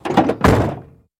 Cadillac 1964 Door Close, Door Handle Movement, Shakes